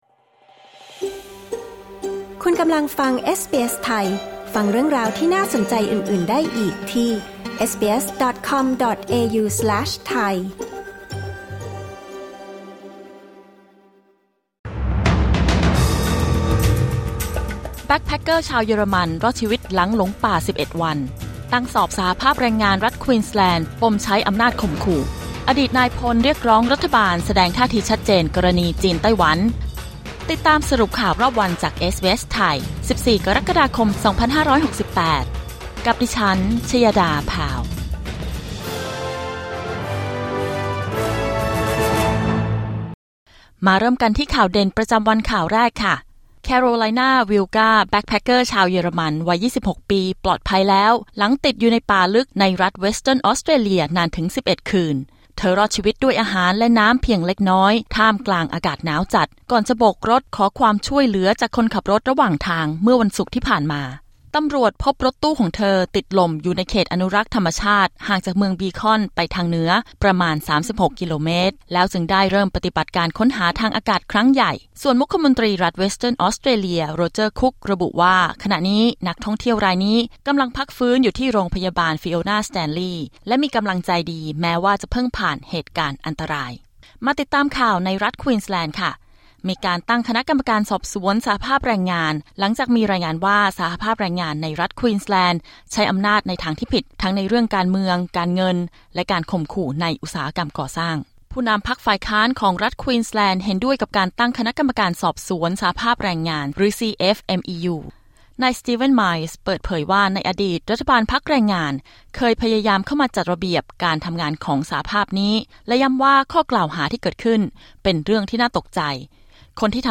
สรุปข่าวรอบวัน 14 กรกฎาคม 2568